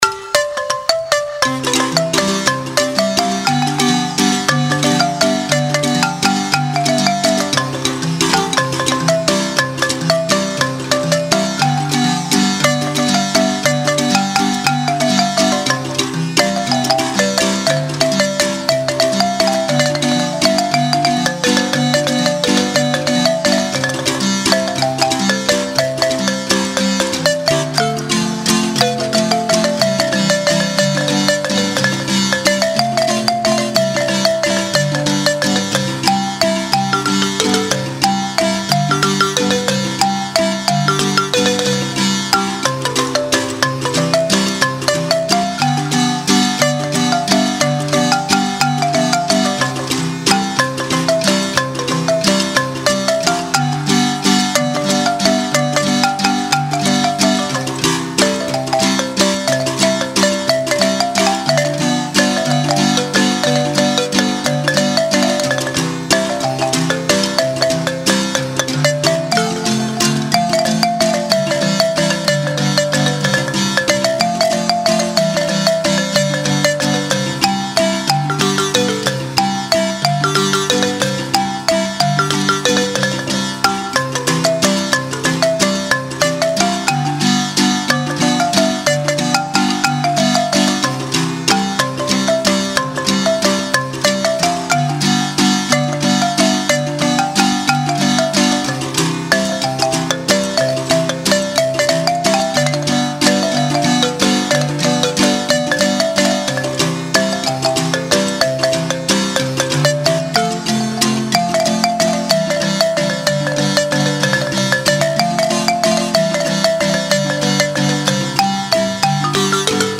Las obras, ordenadas de 1 a 8, fueron grabadas en estudio durante el año 2006 con la marimba escuadra (marimba grande y un tenor) de la Casa de la Cultura de Santa Cruz, Guanacaste.